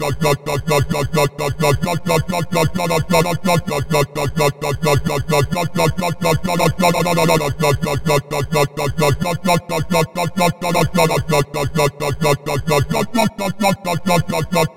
Boffner Bass
描述：只是一个简单的博夫纳贝司
标签： 130 bpm Dance Loops Bass Wobble Loops 344.57 KB wav Key : C
声道立体声